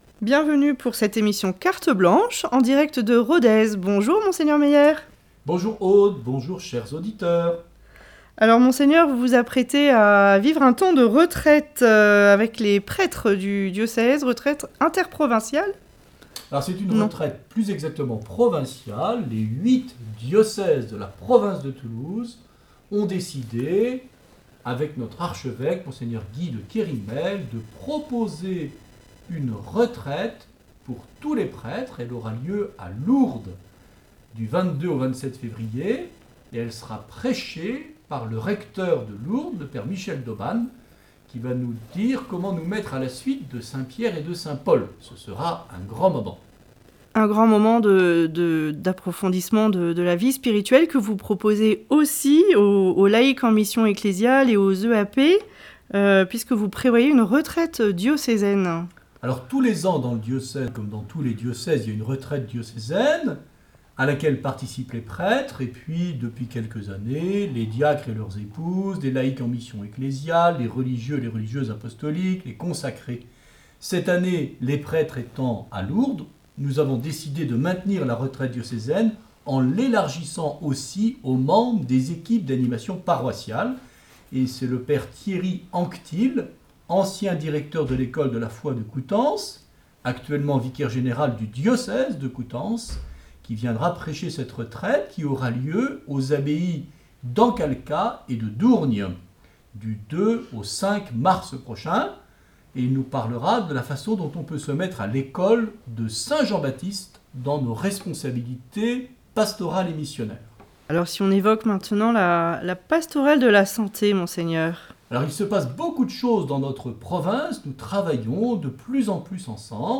Une émission présentée par Mgr Luc Meyer Evêque du diocèse de Rodez et Vabres